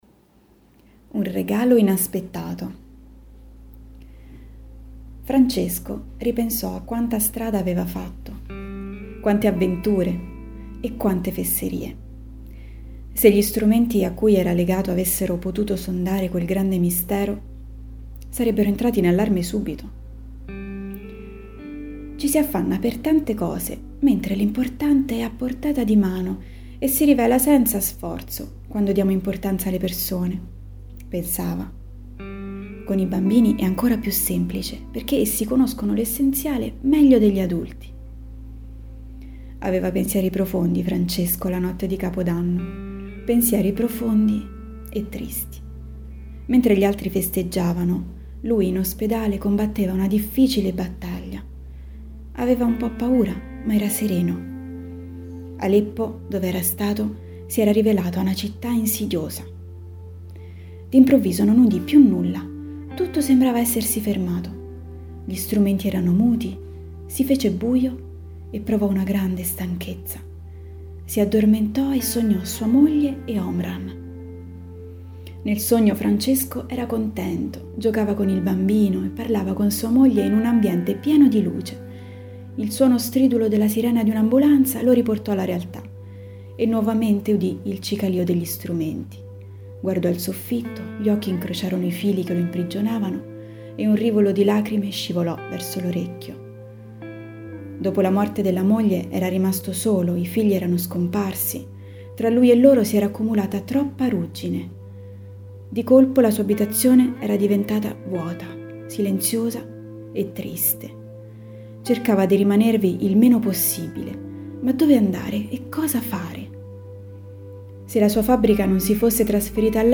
Italia > Audioletture